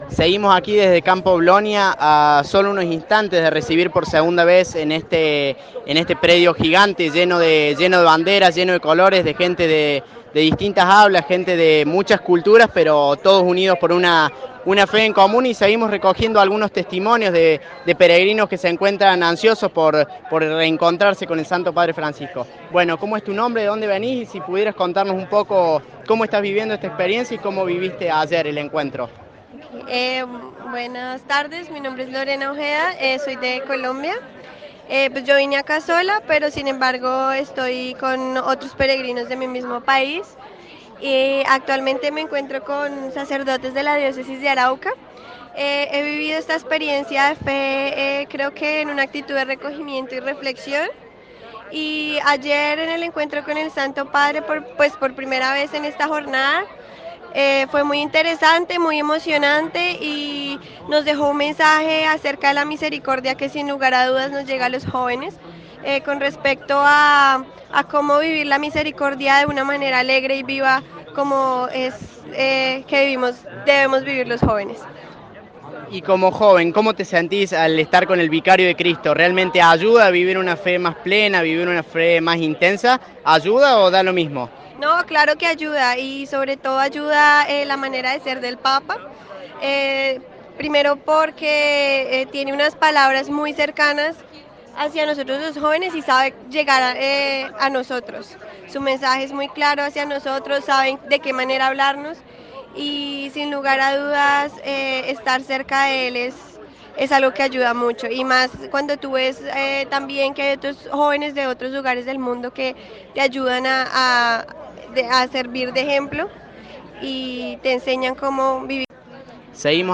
Un seminarista español también sumó su testimonio: “Nos preparamos con mucha alegría y emoción y sobre todo con la presencia del Espíritu santo que se ha manifestado a través de muchas personas que nos han ayudado.